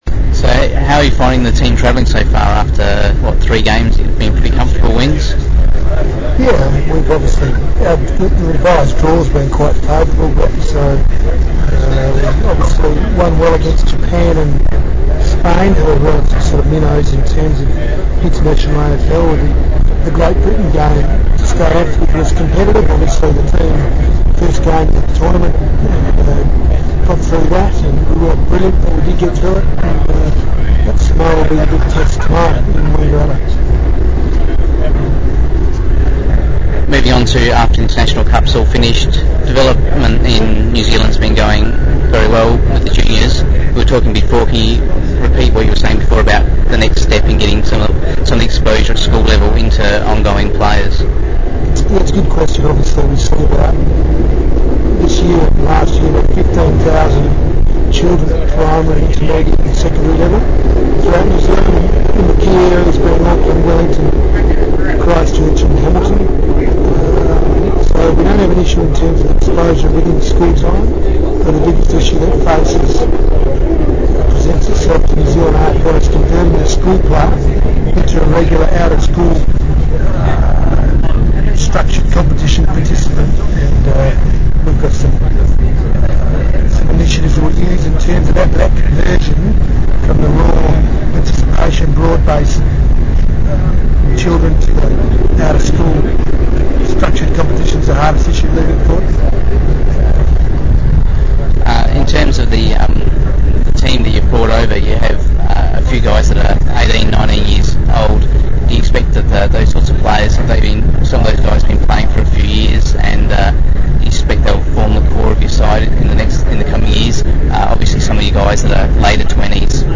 Below is a transcript of the interview and a link to the audio, however the latter is relatively poor quality due to background noise levels on the train.
Interview audio